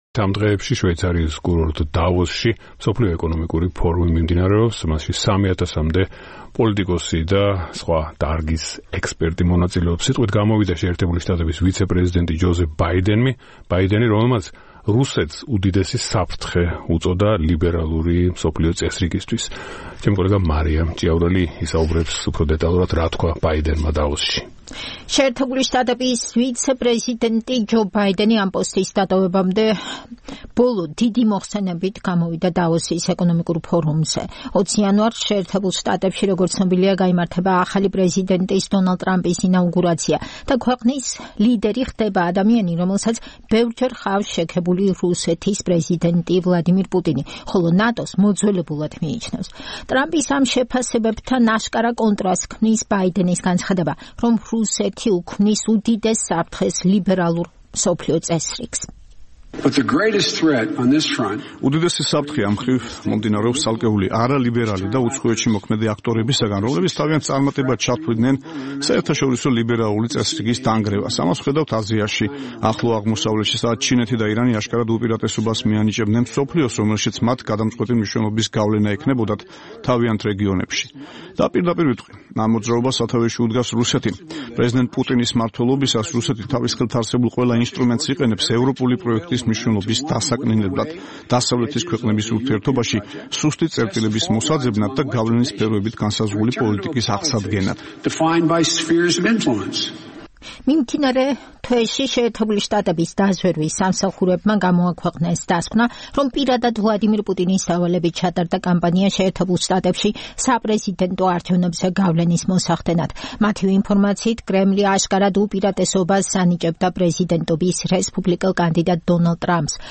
შვეიცარიის კურორტი დავოსი 17-დან 20 იანვრამდე მასპინძლობს მსოფლიო ეკონომიკურ ფორუმს - გავლენიანი პოლიტიკოსების, ექსპერტების, ბიზნესმენების, შოუბიზნესის წარმომადგენლების შეკრებას, რომელშიც წელს 3 000-მდე ადამიანი მონაწილეობს. 18 იანვარს სიტყვით გამომსვლელებს შორის იყო აშშ-ის ვიცე-პრეზიდენტი ჯო ბაიდენი, რომელმაც რუსეთს უწოდა „უდიდესი საფრთხე“ ლიბერალური მსოფლიო წესრიგისათვის.
ჯო ბაიდენი აშშ-ის ვიცე-პრეზიდენტის პოსტის დატოვებამდე ბოლო დიდი მოხსენებით გამოვიდა დავოსის ეკონომიკურ ფორუმზე. 20 იანვარს შეერთებულ შტატებში, როგორც ცნობილია, გაიმართება ახალი პრეზიდენტის, დონალდ ტრამპის, ინაუგურაცია და ქვეყნის ლიდერი გახდება ადამიანი, რომელსაც ბევრჯერ ჰყავს შექებული რუსეთის პრეზიდენტი, ვლადიმირ პუტინი, ხოლო ნატოს „მოძველებულად“ მიიჩნევს. ტრამპის ამ შეფასებებთან აშკარა კონტრასტს ქმნის ჯო ბაიდენის განცხადება, რომ რუსეთი უქმნის „უდიდეს საფრთხეს“ ლიბერალურ მსოფლიო წესრიგს.